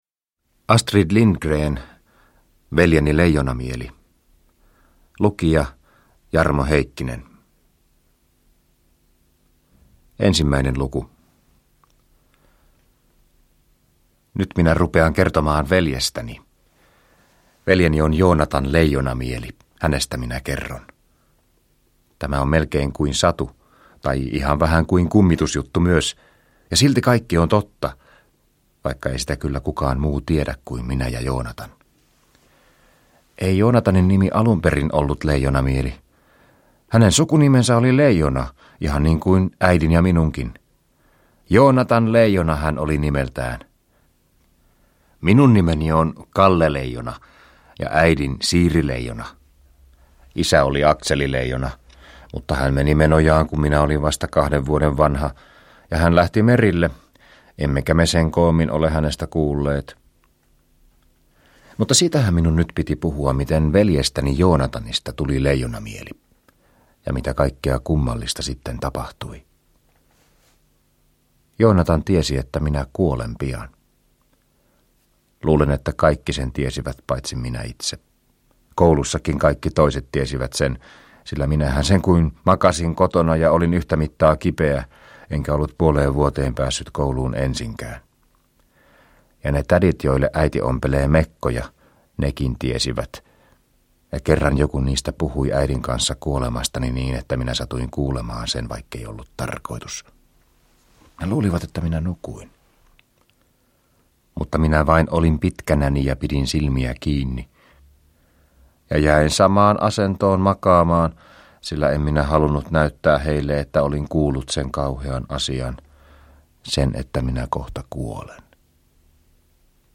Veljeni, Leijonamieli – Ljudbok – Laddas ner